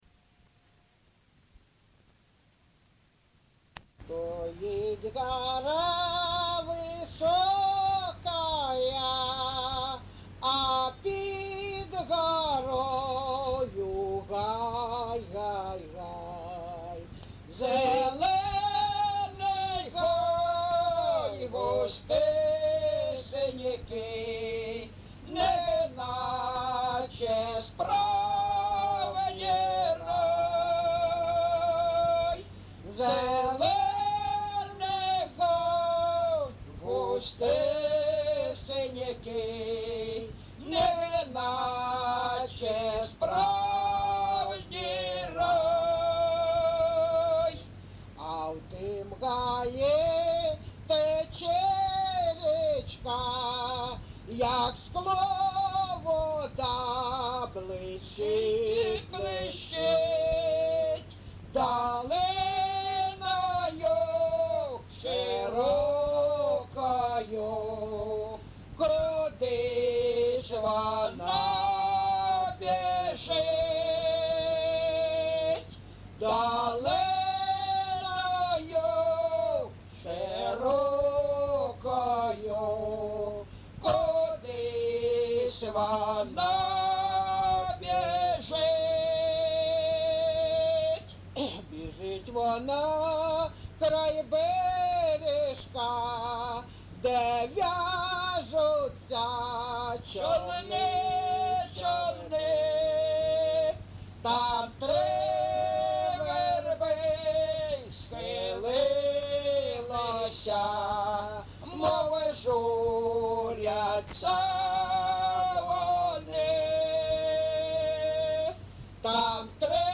ЖанрПісні з особистого та родинного життя, Пісні літературного походження
МотивЖурба, туга
Місце записус. Григорівка, Артемівський (Бахмутський) район, Донецька обл., Україна, Слобожанщина